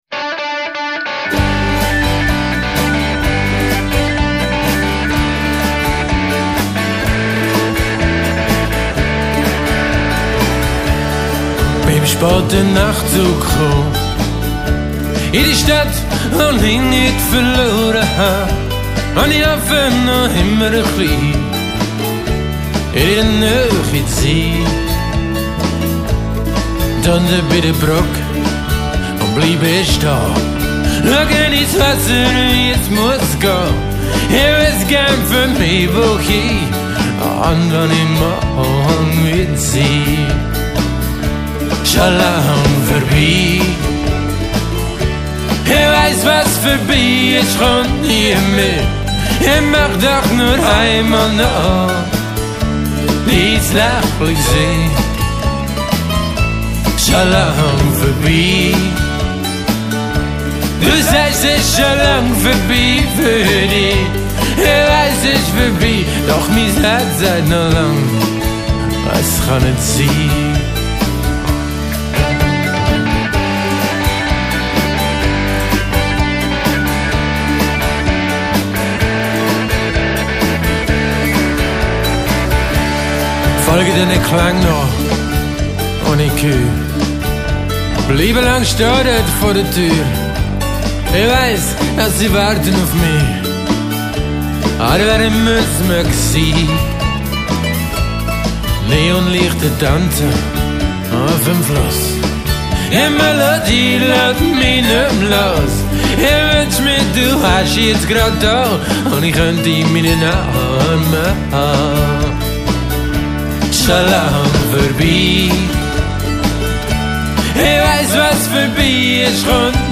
alles andere Homerecording
Schlagzeug
E-Gitarren, Mandoline